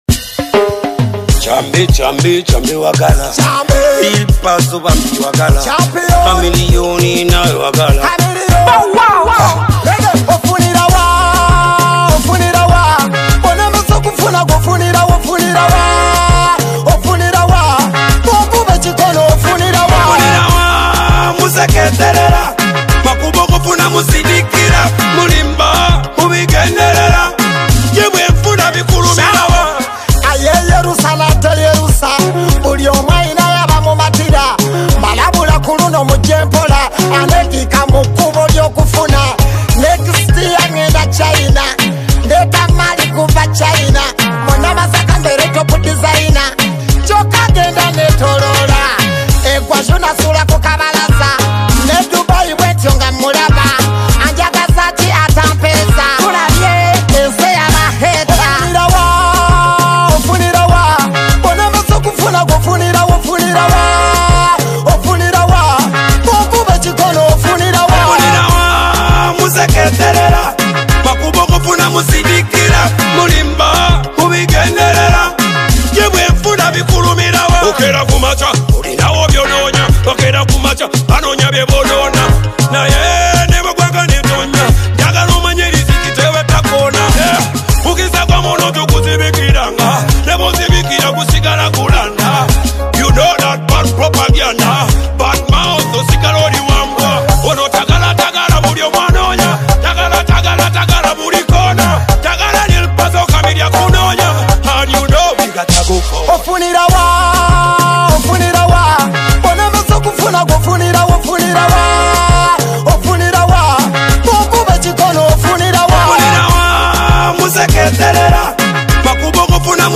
brings energy and lyrical style in a bold collaboration
Download free Ugandan music